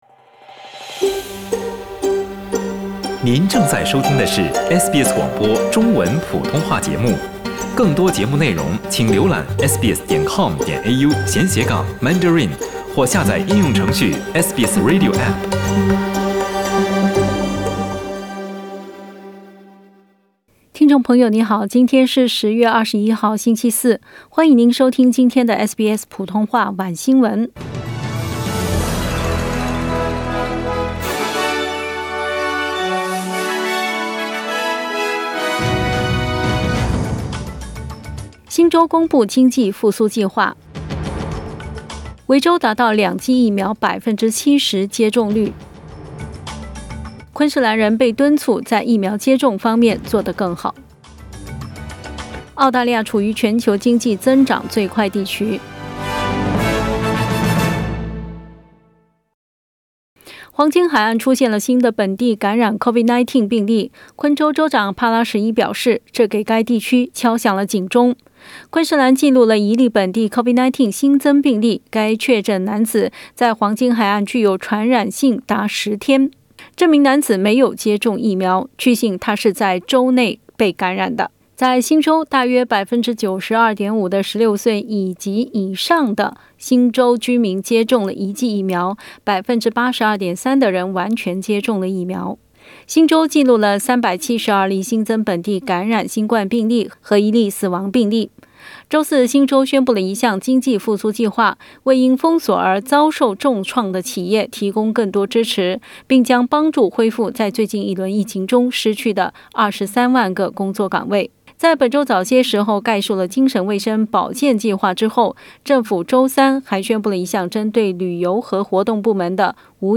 SBS晚新聞（10月21日）
SBS Mandarin evening news Source: Getty Images